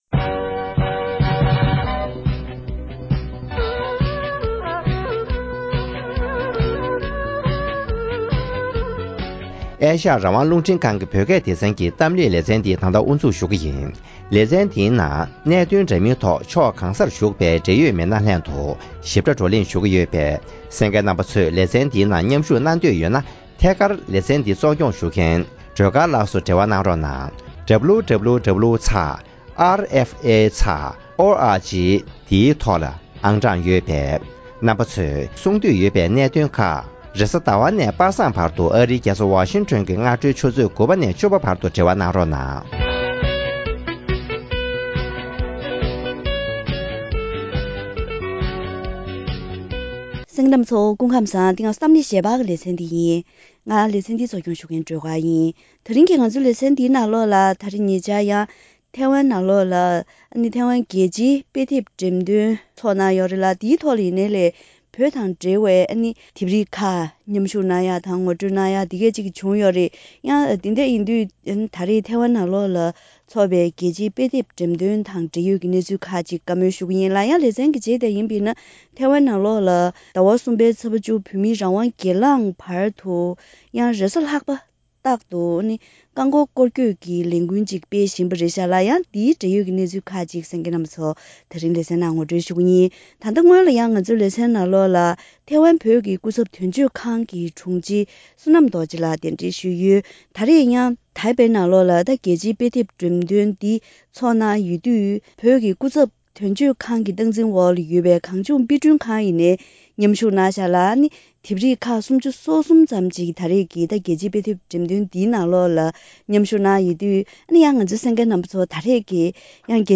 ཐེ་ཝན་ནང་བོད་དོན་ངོ་སྤྲོད་རྒྱ་ཆེན་པོ་སྤེལ་བཞིན་ཡོད་པའི་ཐད་འབྲེལ་ཡོད་དང་ལྷན་དུ་བཀའ་མོལ་ཞུས་པ།